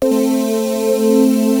100 BEES.wav